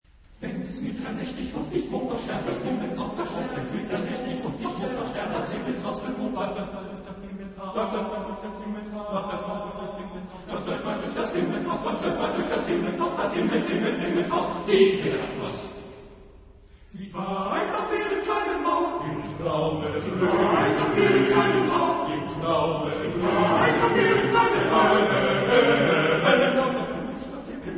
Epoque: 20th century
Genre-Style-Form: Choral song ; Cycle ; Secular
Type of Choir: TTBB  (4 men voices )
Tonality: C major